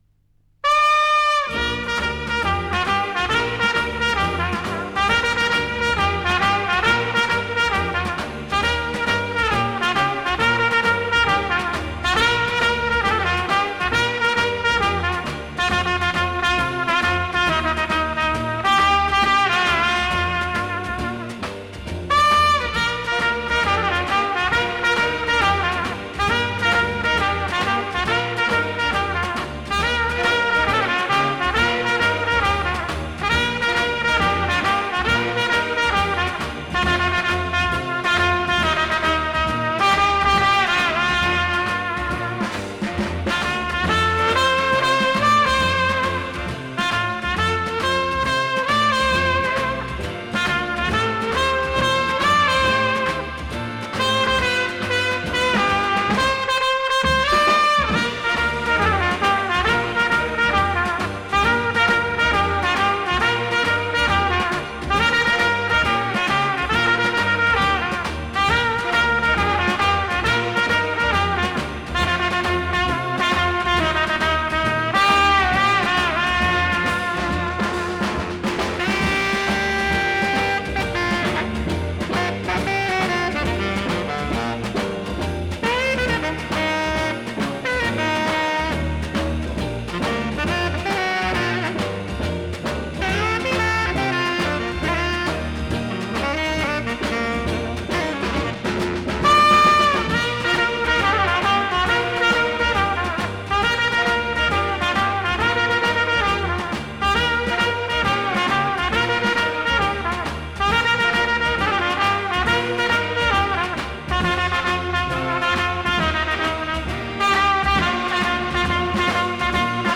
мр3 320 (моно)
Французский трубач, руководитель оркестра.